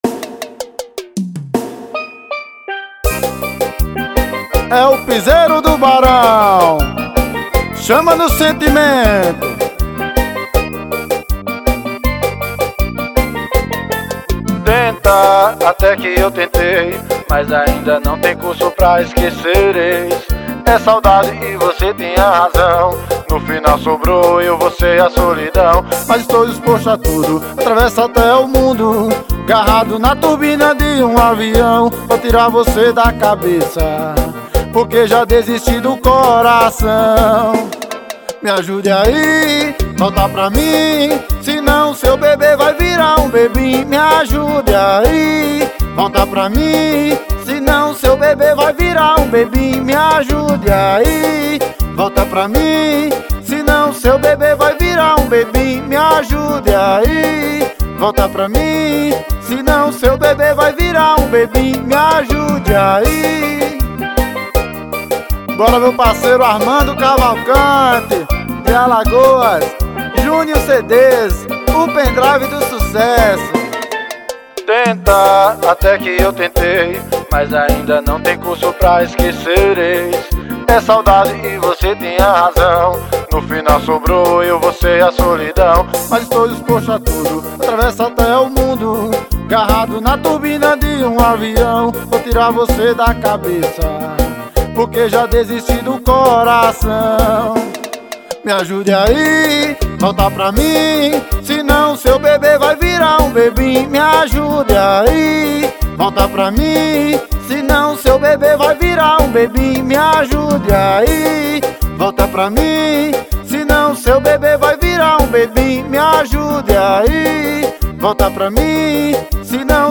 EstiloPiseiro